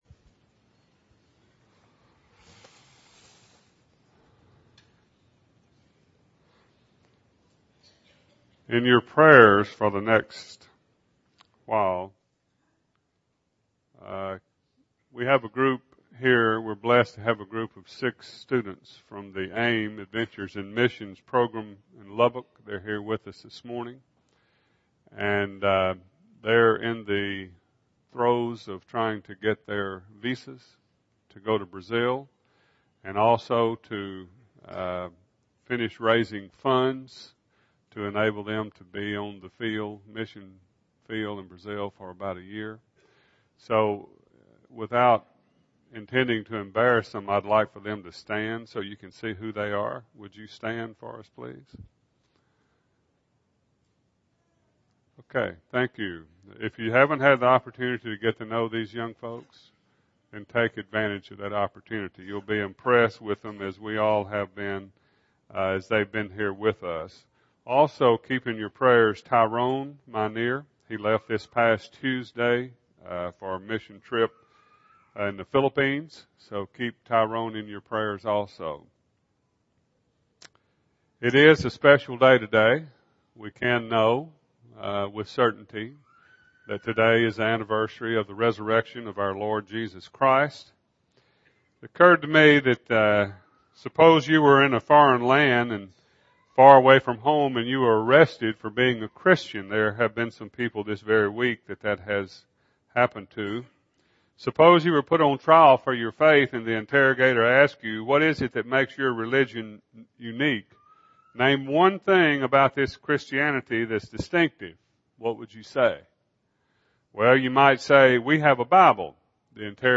Sermon – Bible Lesson Recording